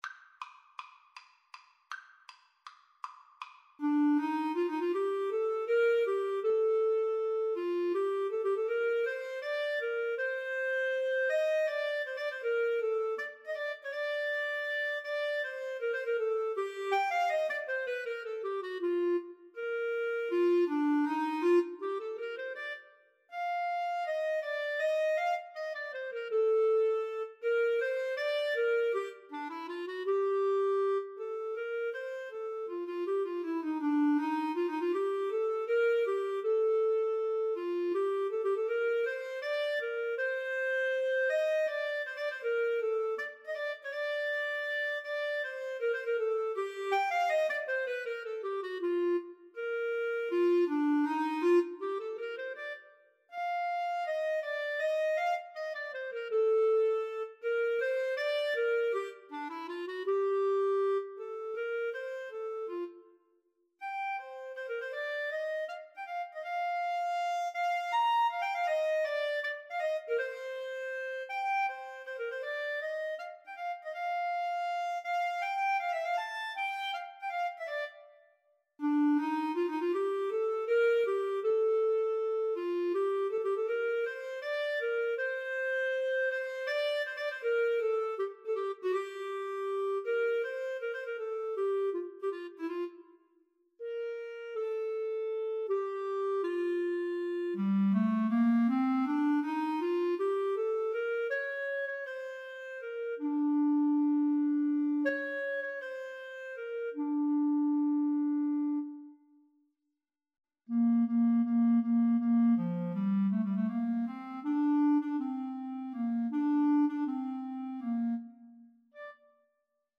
Free Sheet music for Clarinet Duet
Bb major (Sounding Pitch) C major (Clarinet in Bb) (View more Bb major Music for Clarinet Duet )
Allegro con grazia (=144) =160 (View more music marked Allegro)
5/4 (View more 5/4 Music)
Clarinet Duet  (View more Intermediate Clarinet Duet Music)
Classical (View more Classical Clarinet Duet Music)